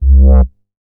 MoogFilta 009.WAV